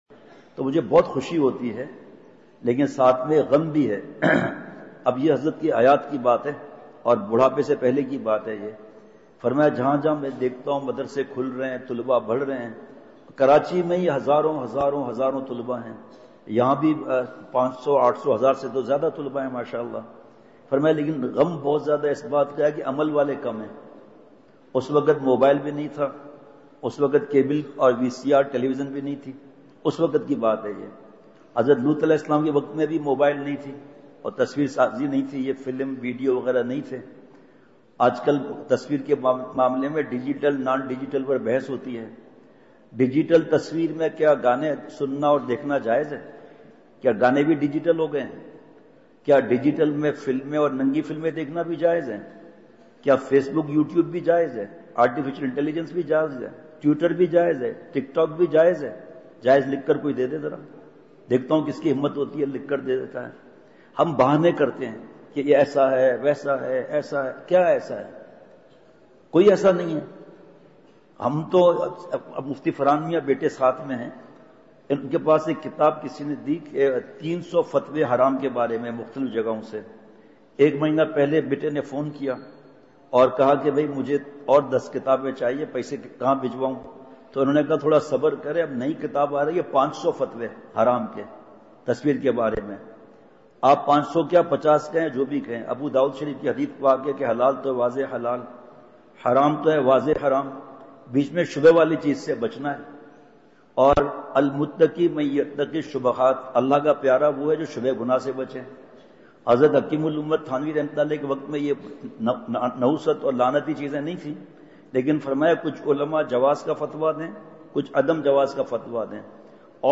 *بمقام:*مدنی مسجد حبیب کوٹ خانیوال
*بعد مغرب* *نمبر(21):بیان*